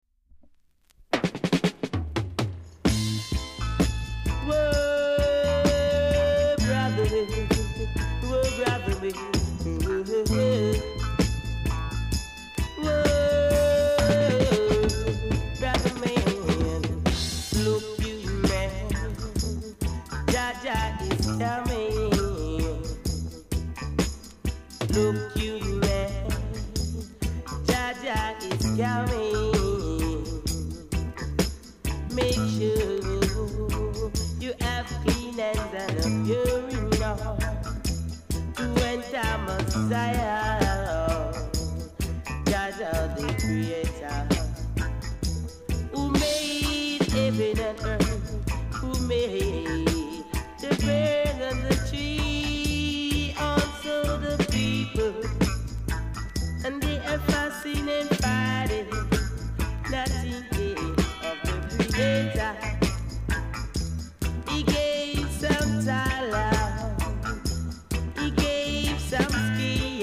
※小さなチリノイズが少しあります。
コメント STEPPER!!GREEN WAX